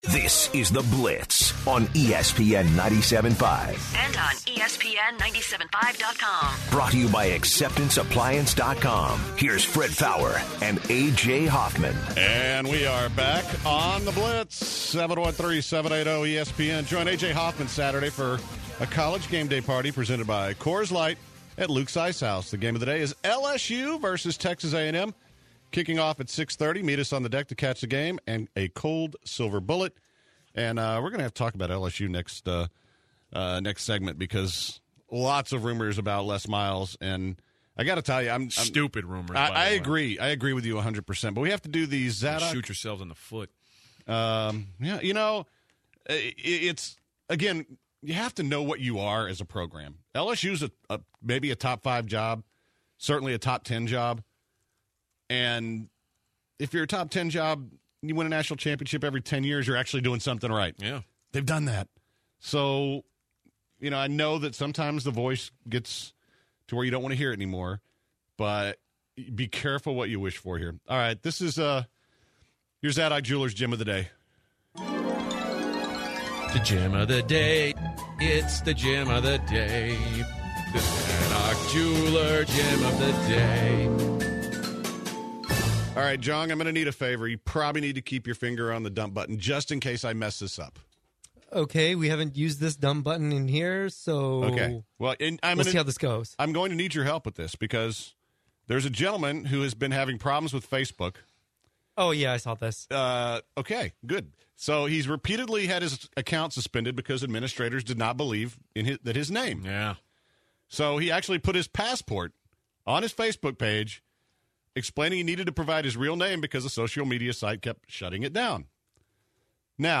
The Guys discuss A&M Vs LSU and take some what are the odds calls, and end the show with the 'Dumb ass Report"